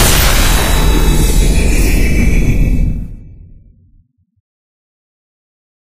Magic11.ogg